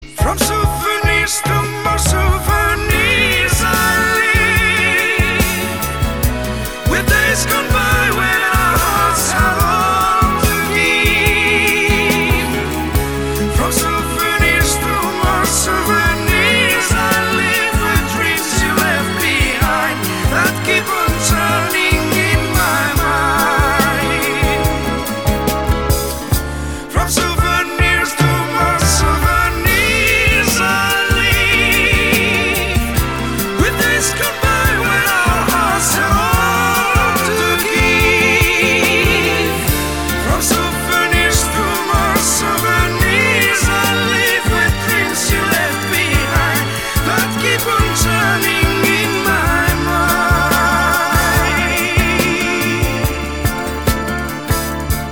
• Качество: 256, Stereo
ретро
шлягер